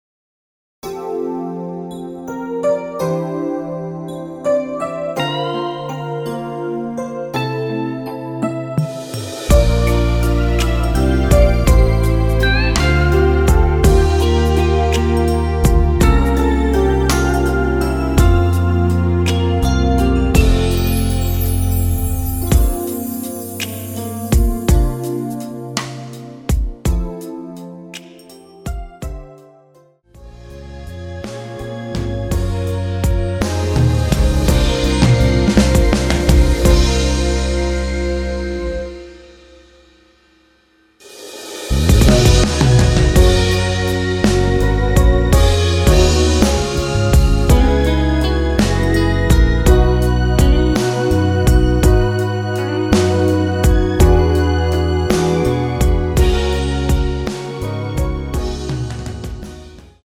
원키에서(+3)올린 MR입니다.
앞부분30초, 뒷부분30초씩 편집해서 올려 드리고 있습니다.
중간에 음이 끈어지고 다시 나오는 이유는